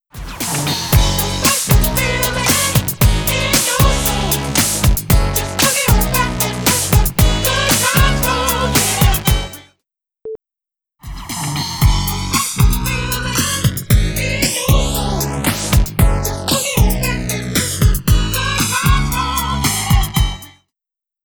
Creating a dual-mono track , ( which looks like stereo but tracks are absolutely identical) , then shifting the tracks out of sync by a fraction of a millisecond , creates a wide-stereo effect. So accidentally adding such a delay to one track will produce a wide-stereo effect.